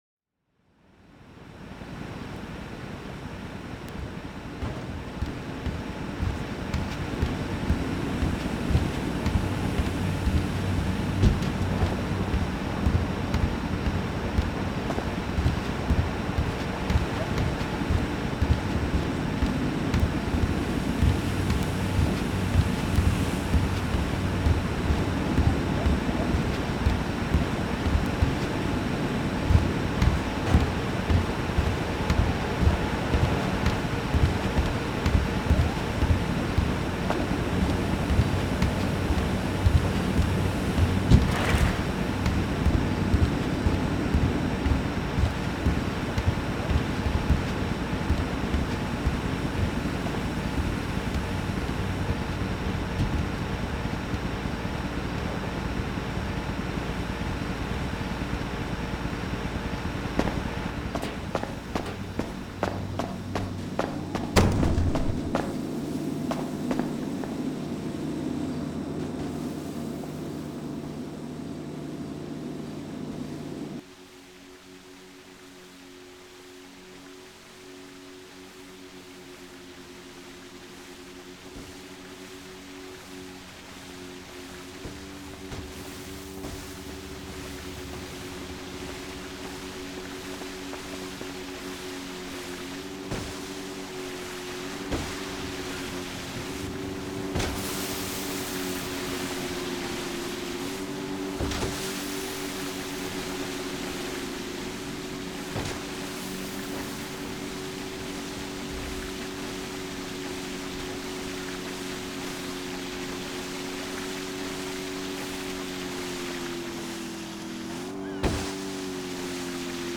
All recordings taken from journeys through open world gameplay, directly onto a Zoom H6 Recorder, with minor editing and equalisation in Protools.
4 Meth lab, machinery, steps and outboards
meth_lab_machinery_steps_and_outboards__excerpt.mp3